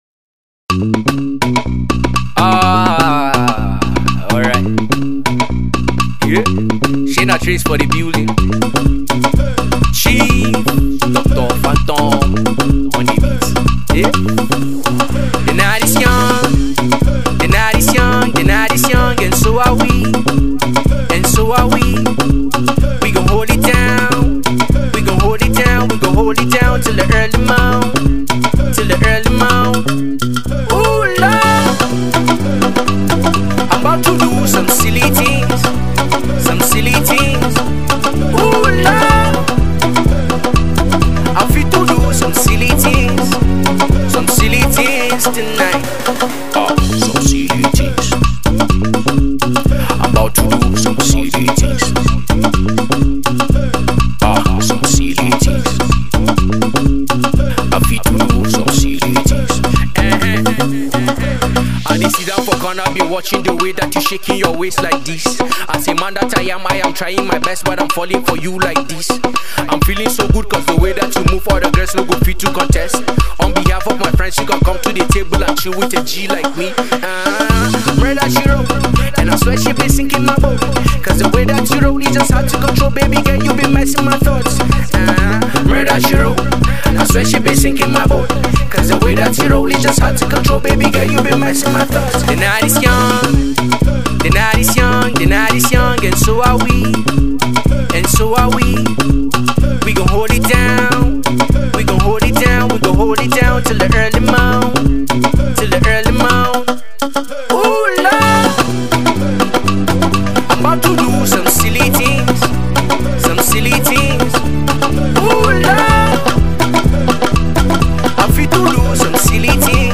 Alternative Pop
up tempo, catchy tune